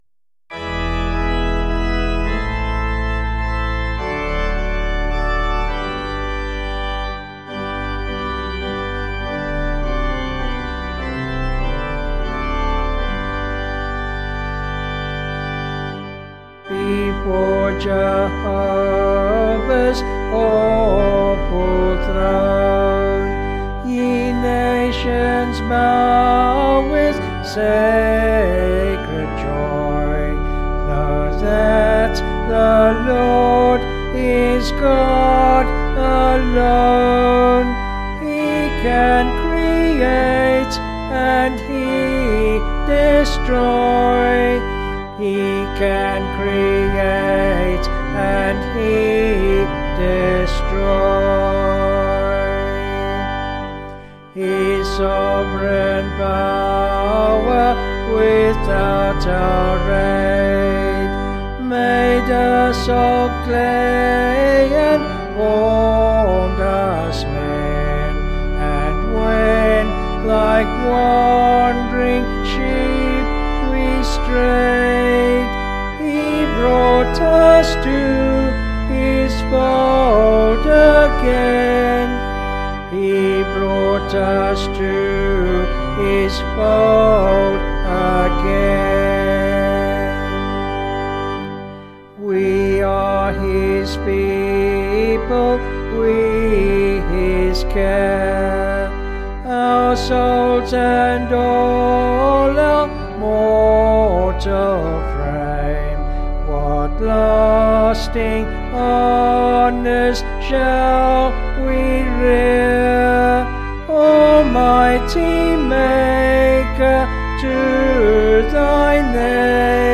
Vocals and Organ   265.3kb Sung Lyrics